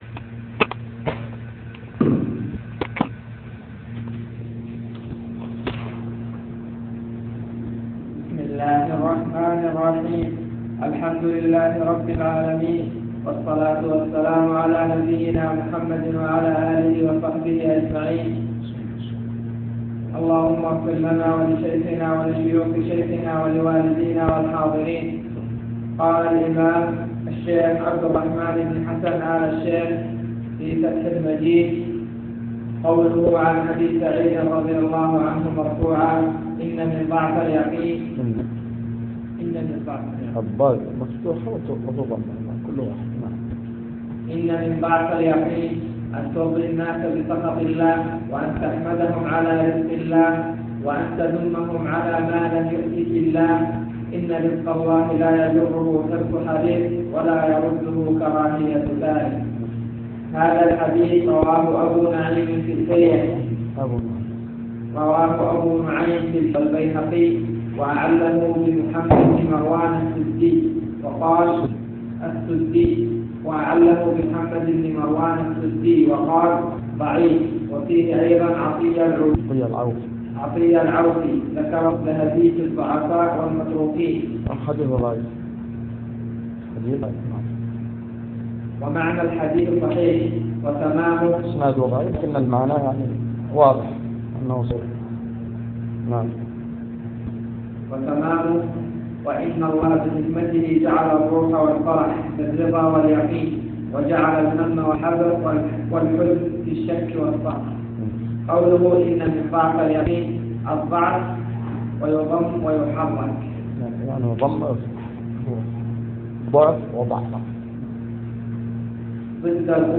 تسجيل لدروس شرح كتاب فتح المجيد شرح كتاب التوحيد